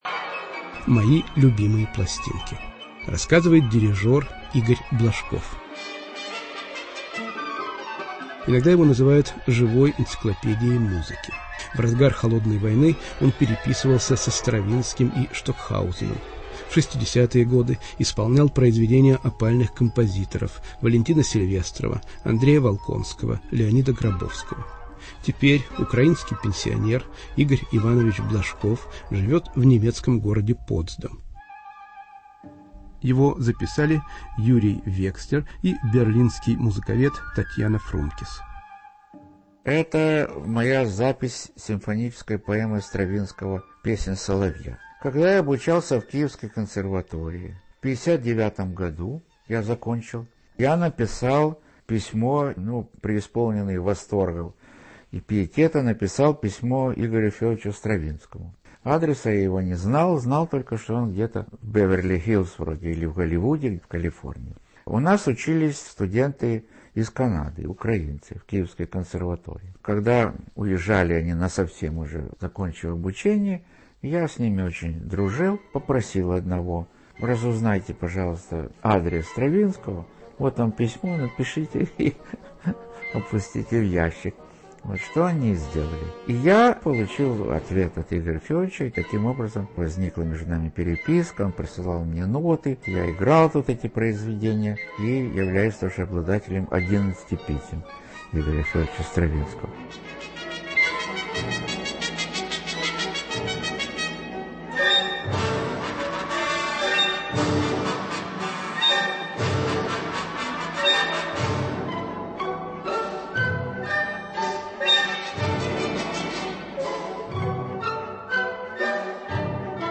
"Мои любимые пластинки". Дирижёр Игорь Блажков рассказывает о переписке с Игорем Стравинским, о сотрудничестве с опальными композиторами-авангардистами и др.